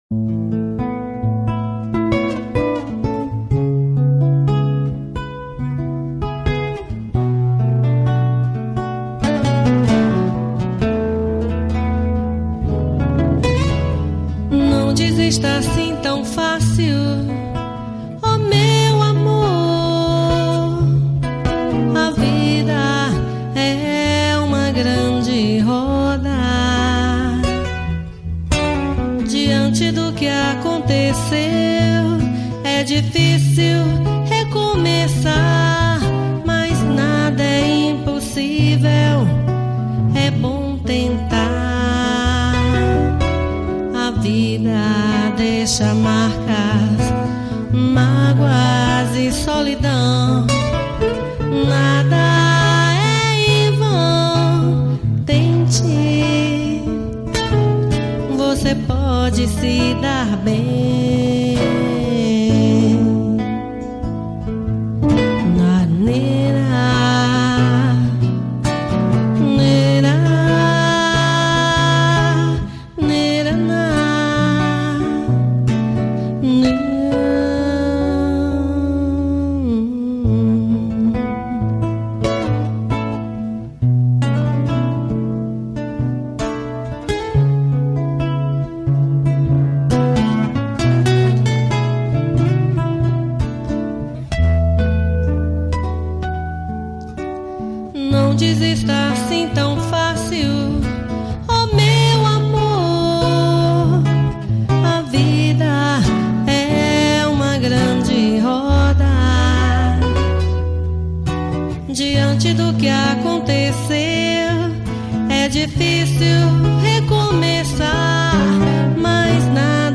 03:06:00   Reggae